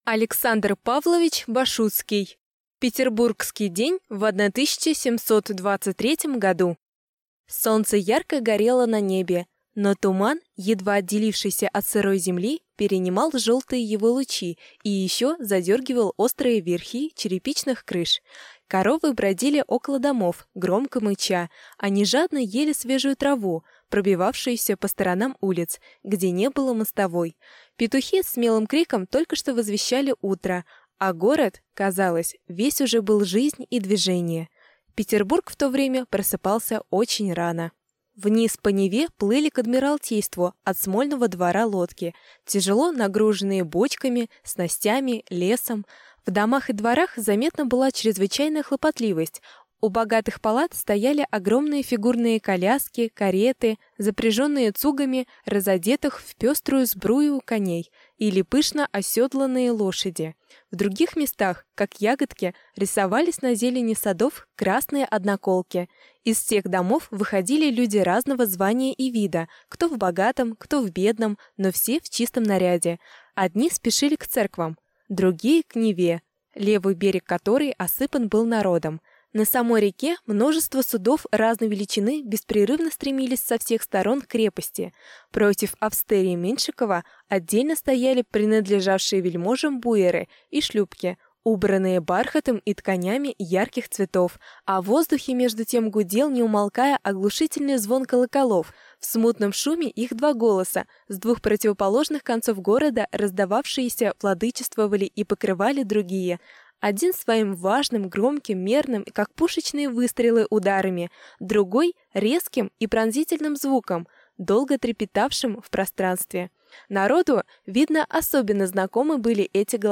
Аудиокнига Петербургский день в 1723 году | Библиотека аудиокниг